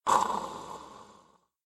slide.ogg